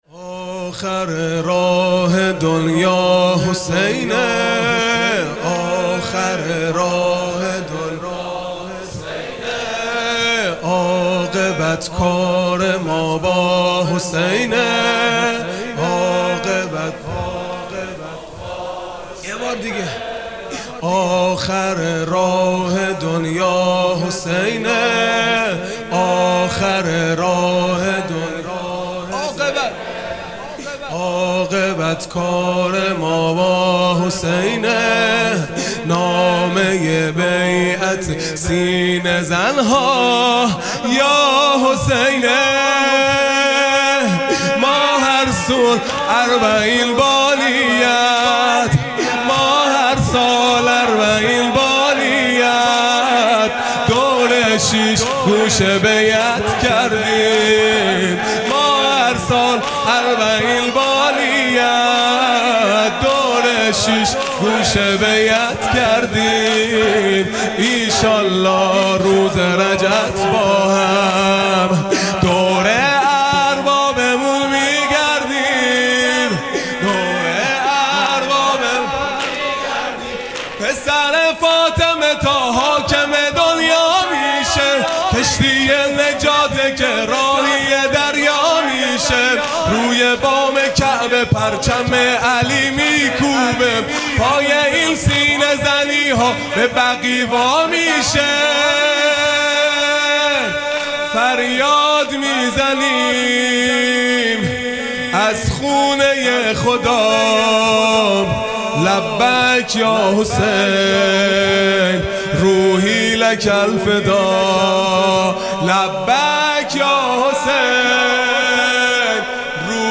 خیمه گاه - هیئت بچه های فاطمه (س) - رجز | آخر راه دنیا حسینه | ۲ مرداد ۱۴۰۲